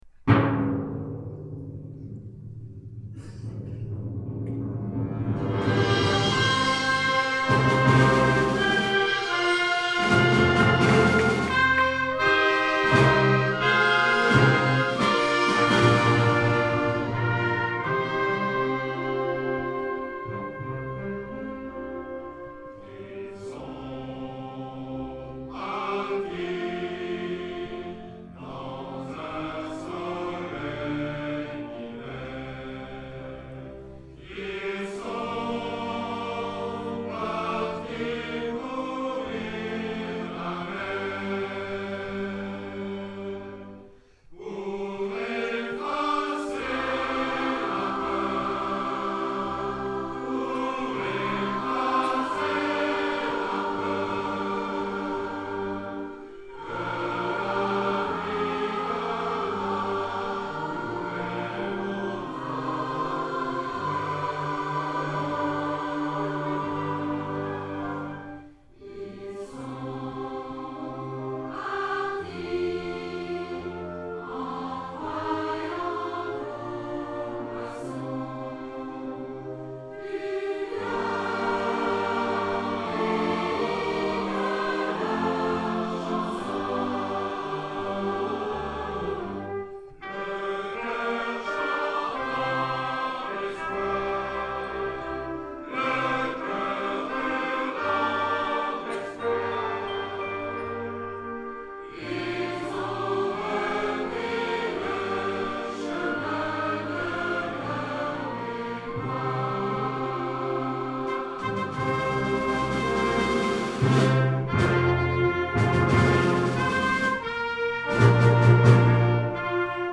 Choeurs en Harmonie
Ce beau et grand projet en 2019 a réuni 150 choristes accompagnés de l'harmonie « les enfants de Chablis ».